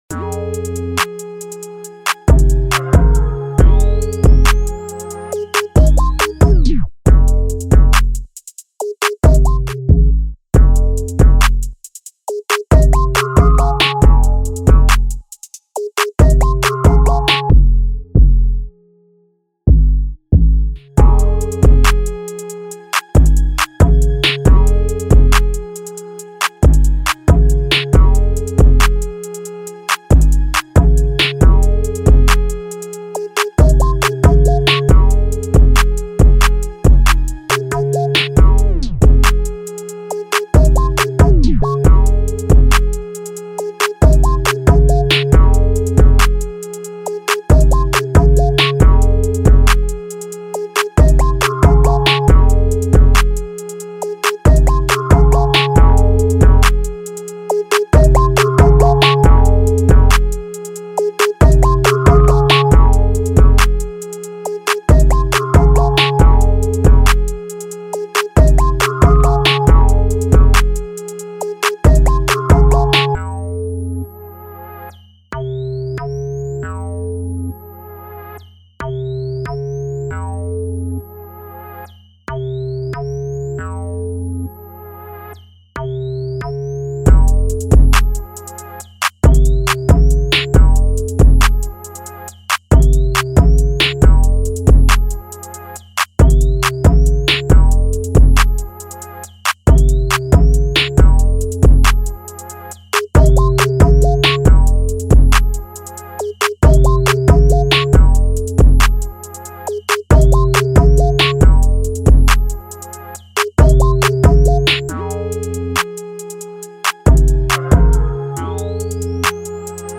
This is the official instrumental
Rap Instrumental